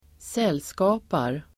Ladda ner uttalet
Uttal: [²s'el:ska:par]